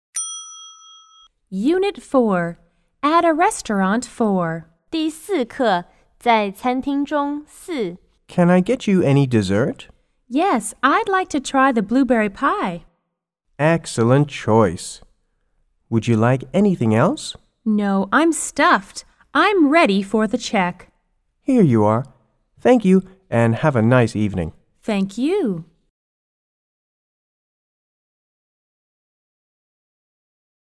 W= Waiter P= Patron